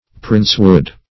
Princewood \Prince"wood`\, n. (Bot.)